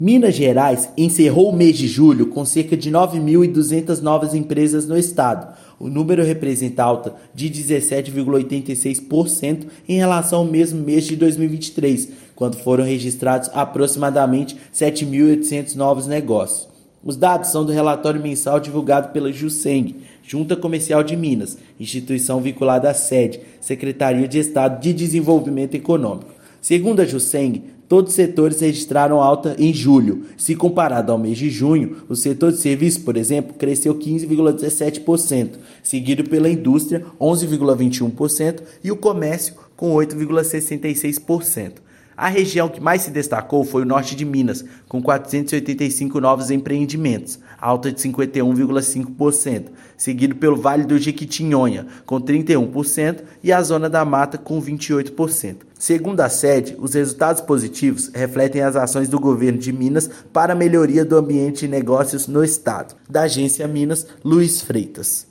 Estado já registrou 57.376 novos negócios neste ano, alta de 13,24% na comparação com mesmo período de 2023; Norte de Minas e Jequitinhonha foram os destaques de julho. Ouça matéria de rádio.